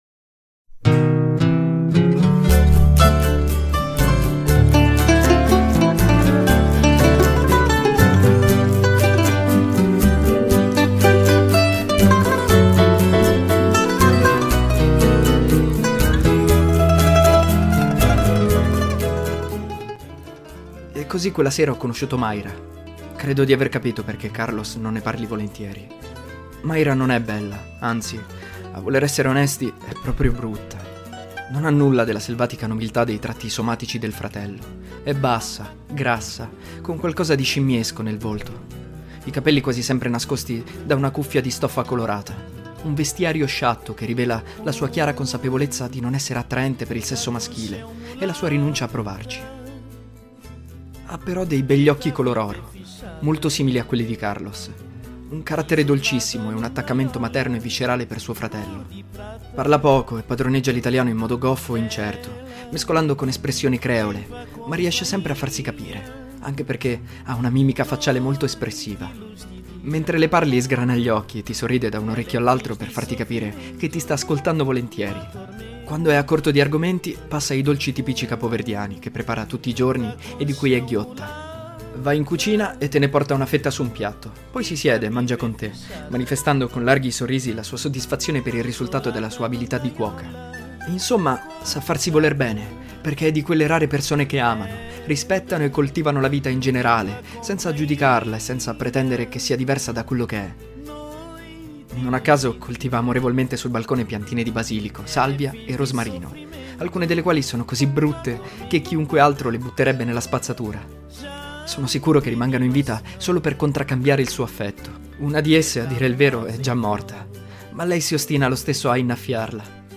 Nel corso dell'episodio si possono ascoltare cover o live dei seguenti brani: